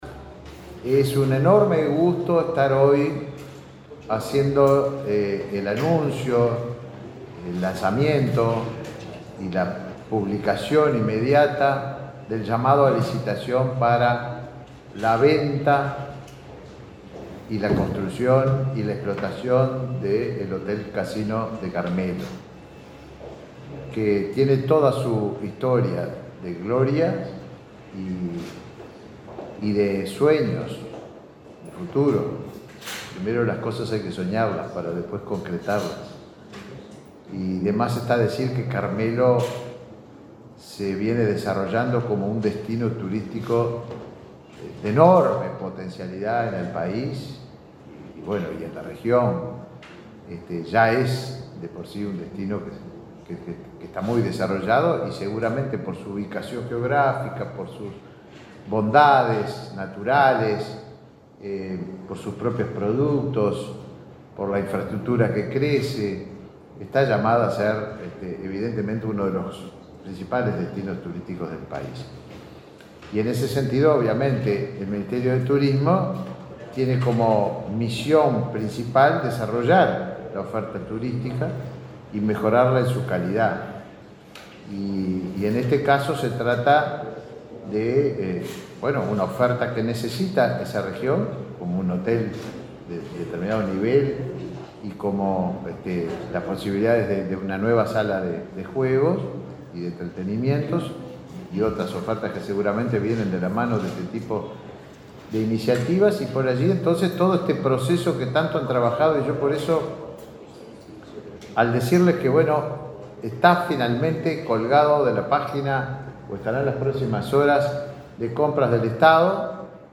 Palabras del ministro de Turismo, Tabaré Viera